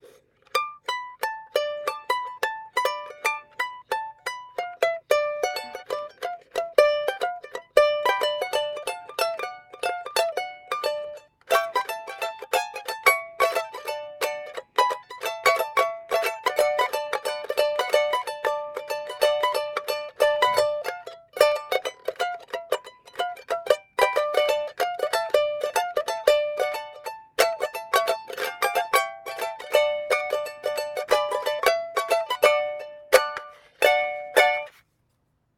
Sopranino für 4 Euro - Seite 7
Saiten Angelschnur vom Penny, Stimmung (Stärken in mm) a (0,35) d (0,45) f# (0,40) b (0,35). Es handelt sich um eine Oktav-Sopran-Stimmung, d. h. eine Oktave höher, als eine Sopran-Ukulele (ultra high a).
Und nein ich habe es nicht mit einer Sopran-Uke aufgenommen und dann gepitcht. Das ist alles echt so gespielt. Das Stück ist eine kleine Ansammlung von Themen, die aus dem Blues kommen und mit Sicherheit sehr alt und gemeinfrei sind.